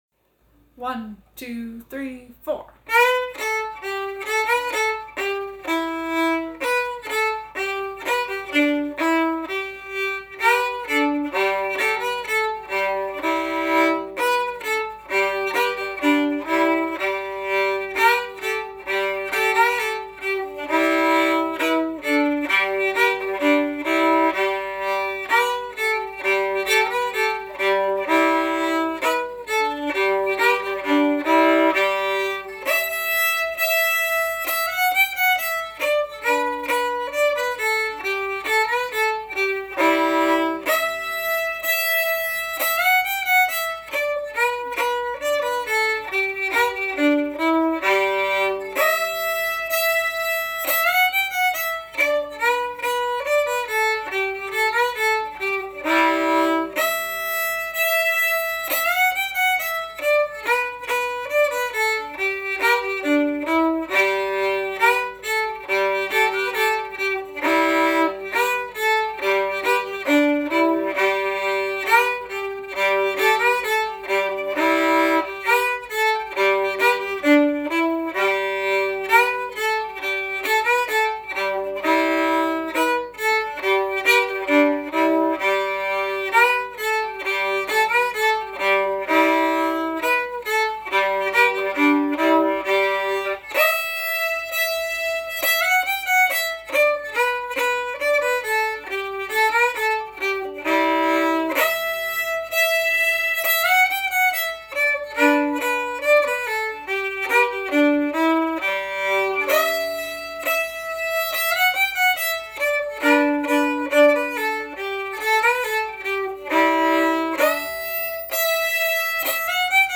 Old Aunt Jenny with Her Nightgown On Fiddle Tab